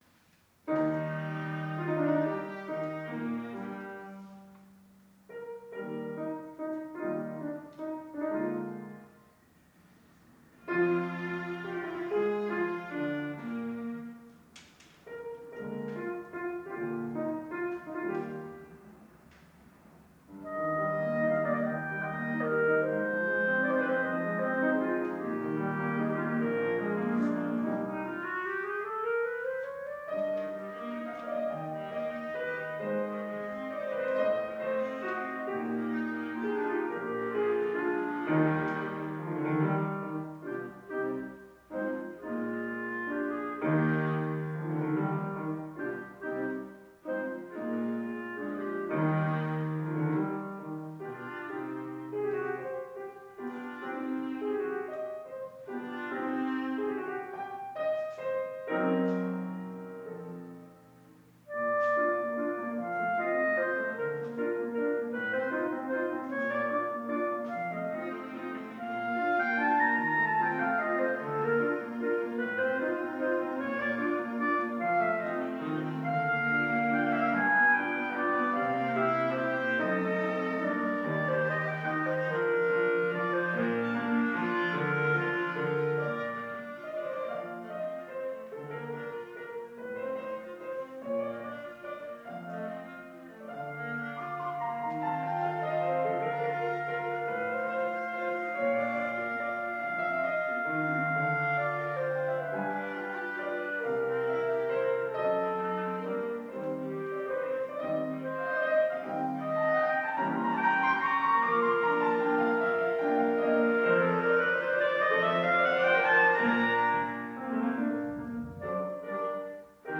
Mozart, Wolfgang Amadeus - Trio in E-flat major, K.498 Free Sheet music for Piano Trio
Style: Classical
Audio: Washington, DC - Washington Musica Viva
clarinet
viola